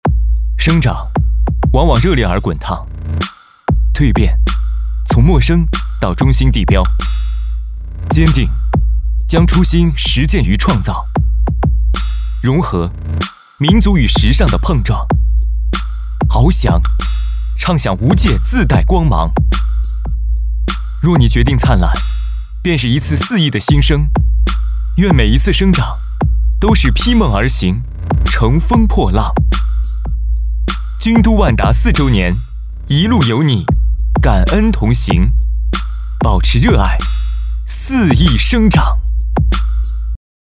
专注高端配音，拒绝ai合成声音，高端真人配音认准传音配音
男39